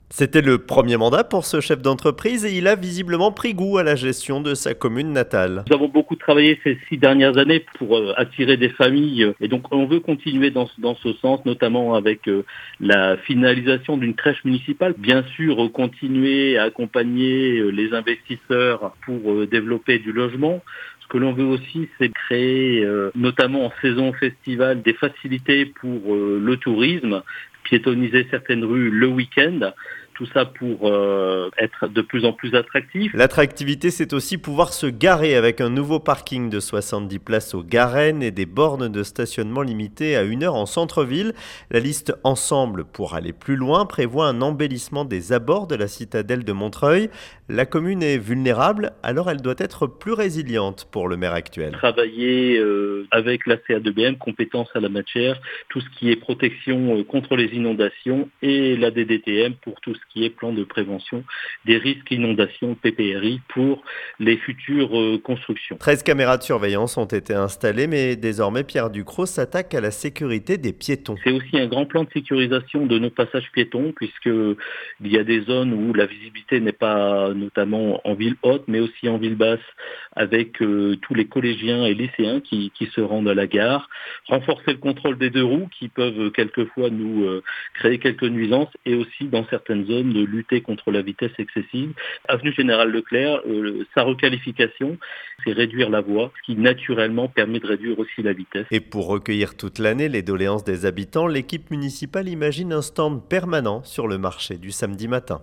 ENTRETIEN : Pierre Ducrocq veut "aller plus loin" pour l’attractivité et la résilience de Montreuil-sur-Mer